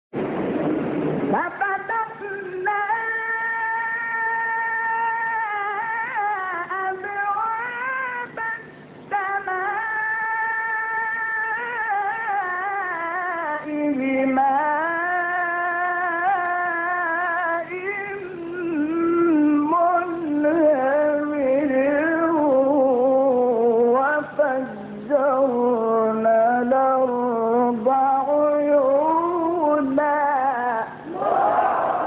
گروه فعالیت‌های قرآنی: فرازهای صوتی از قراء برجسته جهان اسلام را می‌شنوید.
فرازی از محمد شحات انور